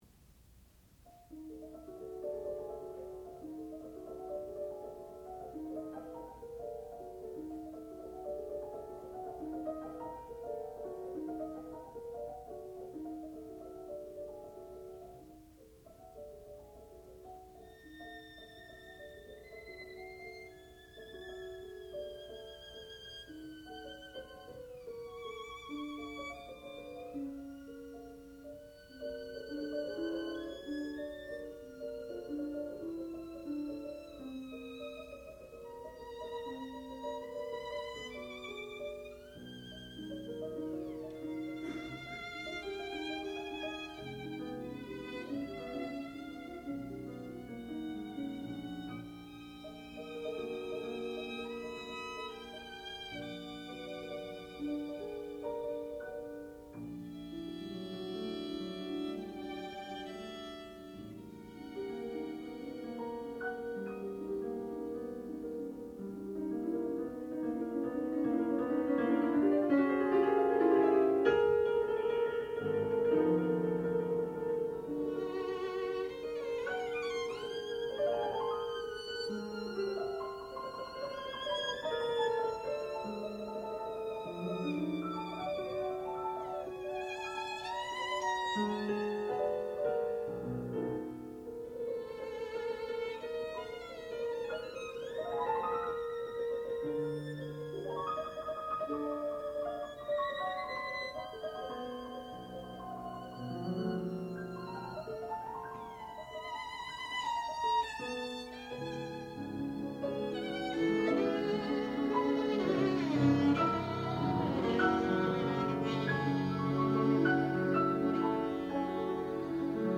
sound recording-musical
classical music
Arve Tellefsen, violin and Eva Knardahl, piano (performer).